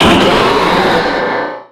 Cri de Méga-Sharpedo dans Pokémon Rubis Oméga et Saphir Alpha.
Cri_0319_Méga_ROSA.ogg